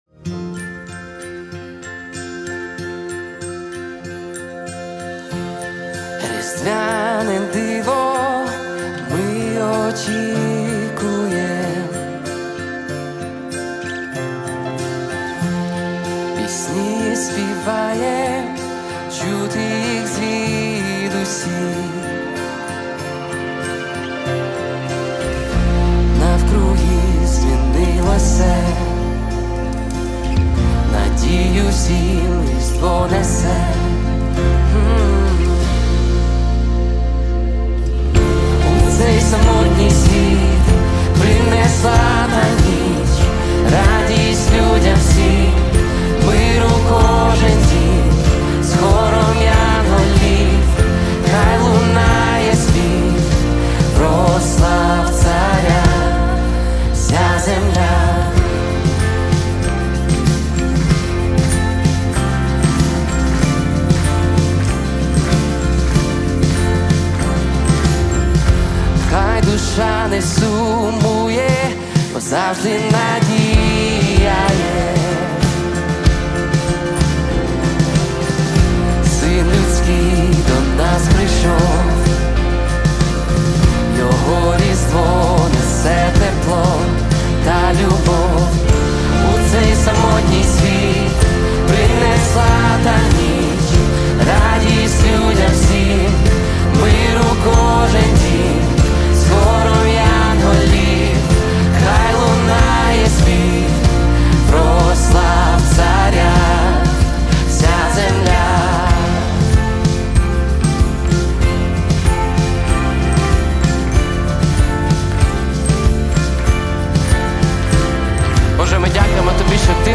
255 просмотров 40 прослушиваний 1 скачиваний BPM: 95